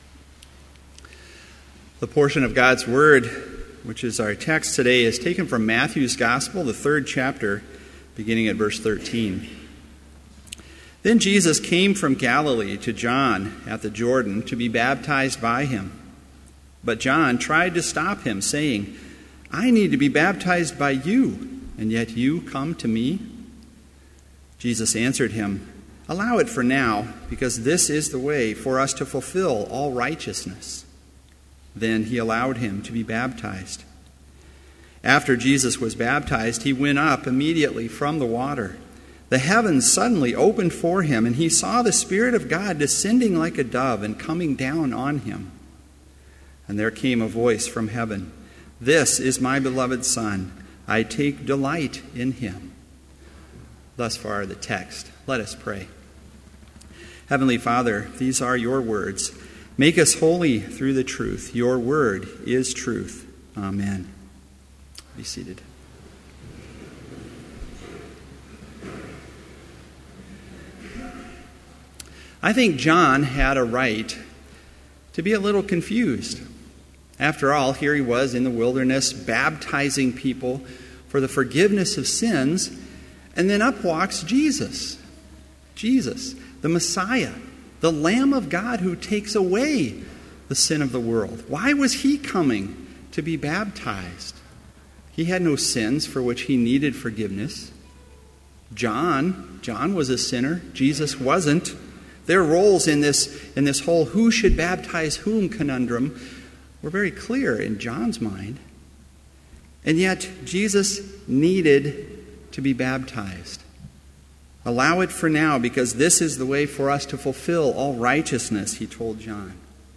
Complete Service
• Prelude
• Homily
This Chapel Service was held in Trinity Chapel at Bethany Lutheran College on Tuesday, February 17, 2015, at 10 a.m. Page and hymn numbers are from the Evangelical Lutheran Hymnary.